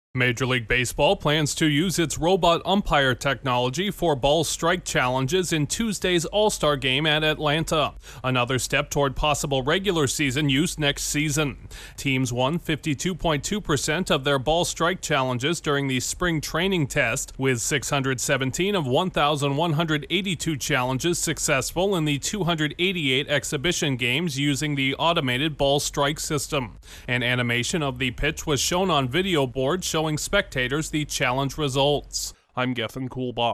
Robot umpires are set to make their in-season debut on one of baseball’s biggest stages. Correspondent